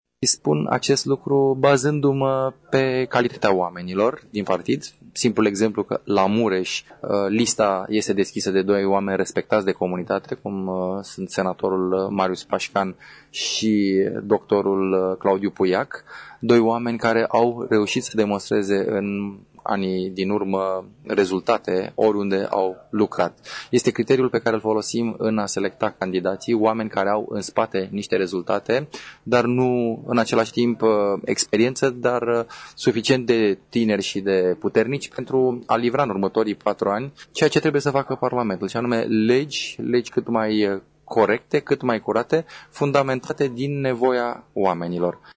PMP își propune să obțină cel puțin 10% din voturi la alegerile parlamentare, a afirmat azi, într-o conferință de presă desfășurată la Tg.Mureș, președintele executiv al formațiunii, Valeriu Steriu.